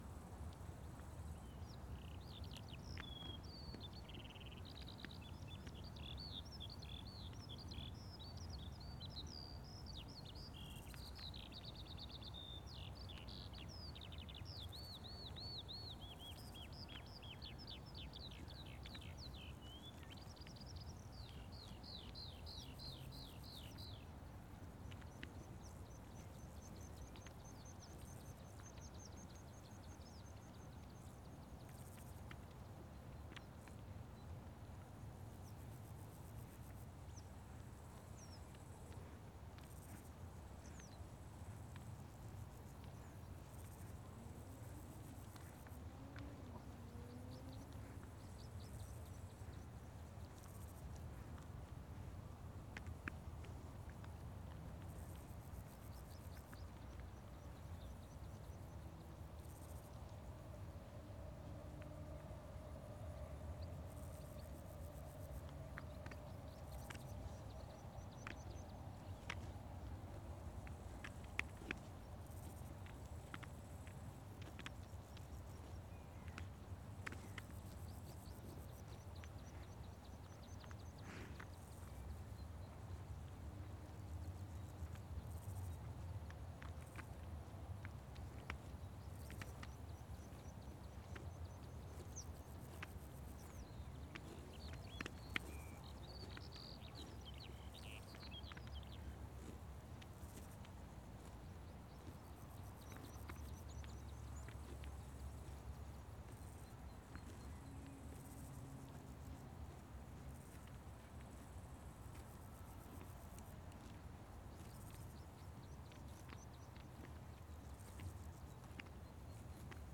Cattle in Pasture NL 130720-_00 Sound Effect — Free Download | Funny Sound Effects
In the pasture some cattle grazing (little wind 1-4 bft) while the are eating the walk ar